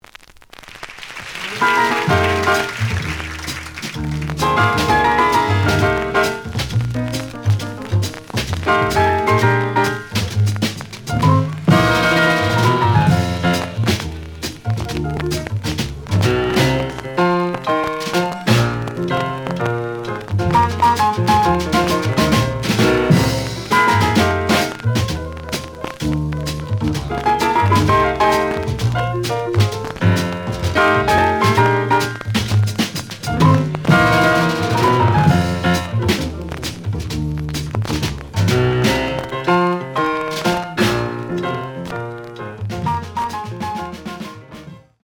The audio sample is recorded from the actual item.
●Genre: Jazz Funk / Soul Jazz
Noticeable noise on B side due to scratches.)